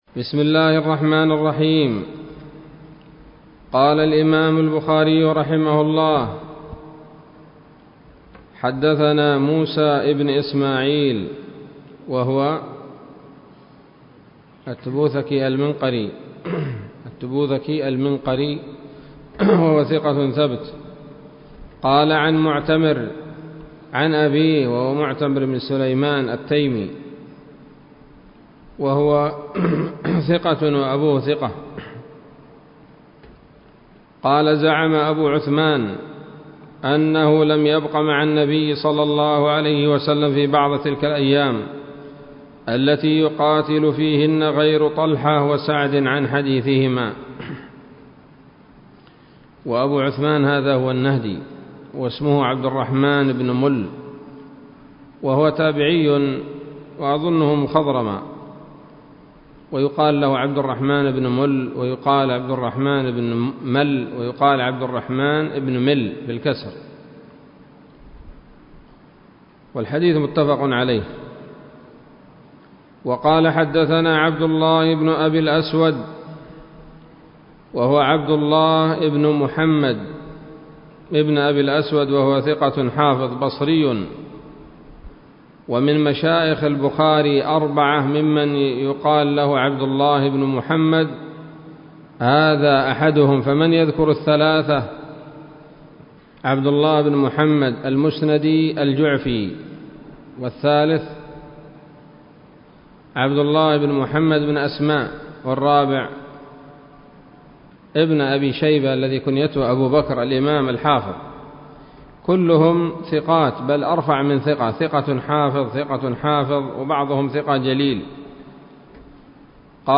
الدرس السادس والثلاثون من كتاب المغازي من صحيح الإمام البخاري